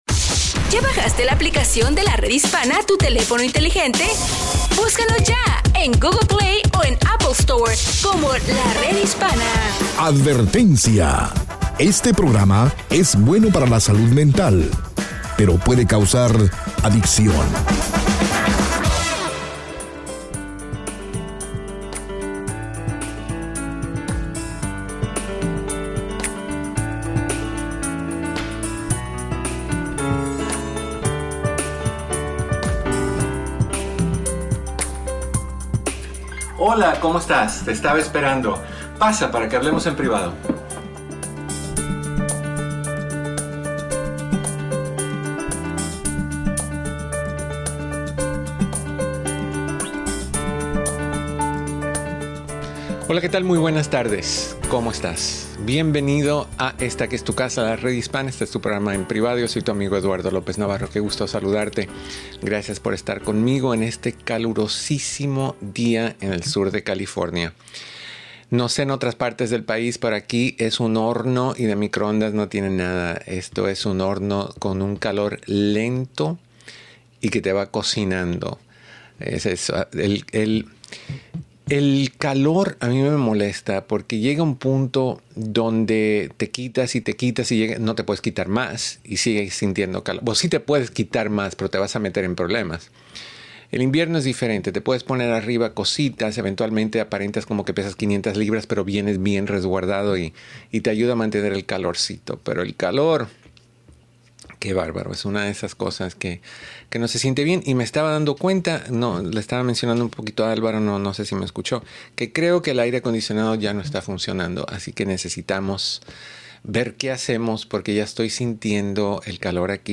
Escucha el programa de radio EN PRIVADO, de Lunes a Viernes a las 2 P.M. hora del Pacífico, 4 P.M. hora Central y 5 P.M. hora del Este por La Red Hispana y todas sus afiliadas.